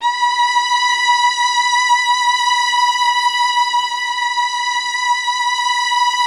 MELLOTRON.16.wav